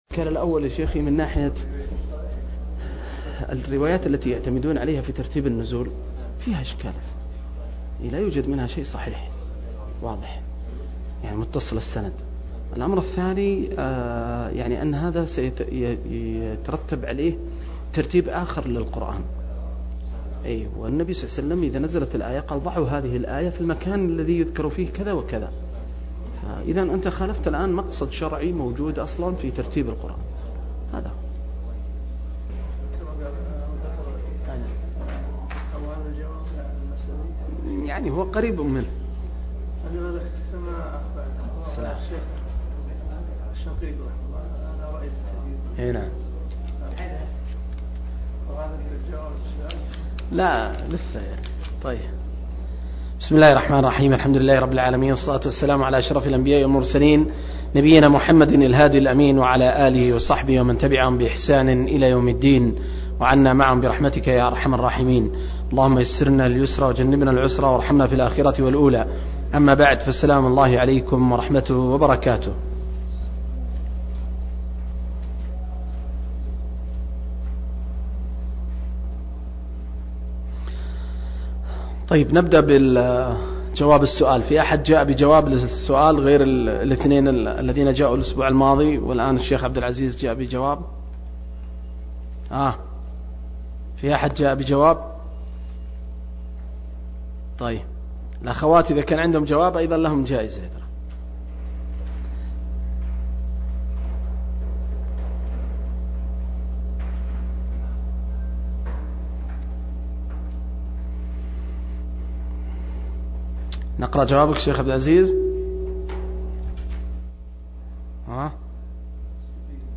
018- عمدة التفسير عن الحافظ ابن كثير رحمه الله للعلامة أحمد شاكر رحمه الله – قراءة وتعليق –